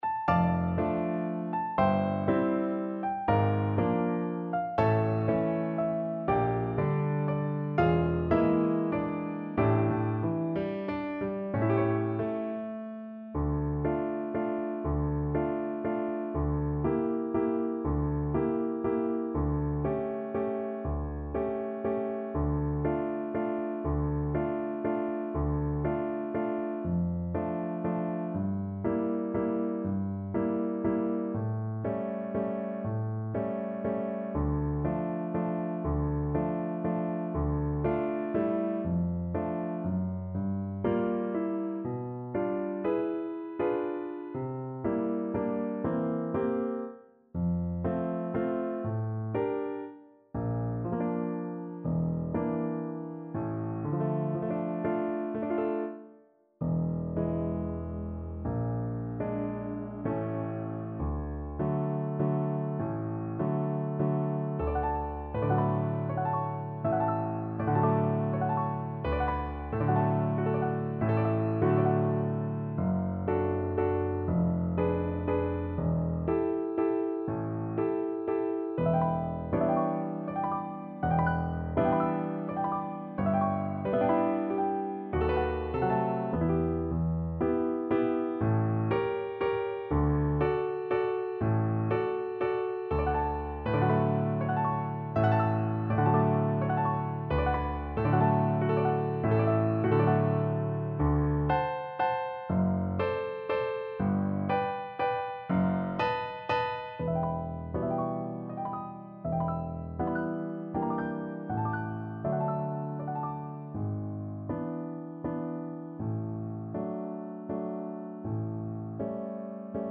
3/4 (View more 3/4 Music)
~ = 120 Lento